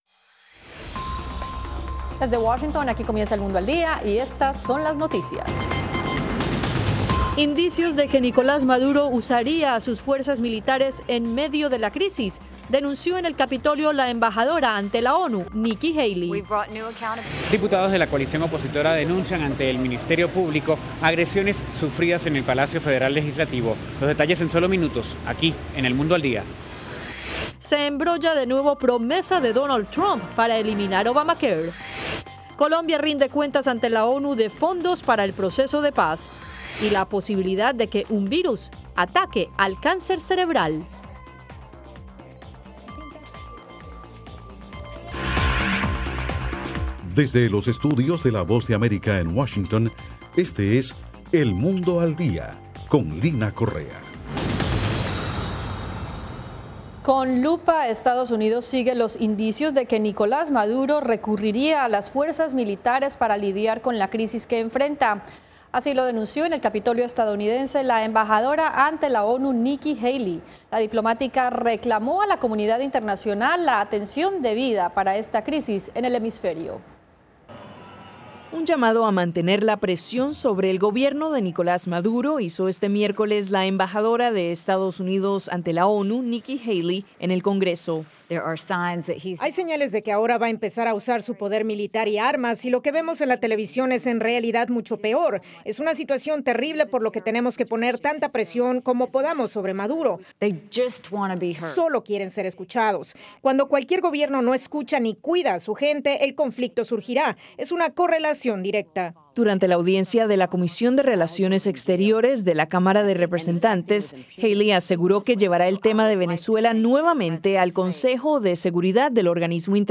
Las noticias del acontecer de Estados Unidos y el mundo